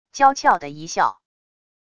娇俏的一笑wav音频